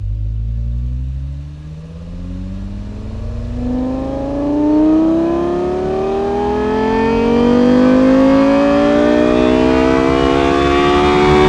v10_04_accel.wav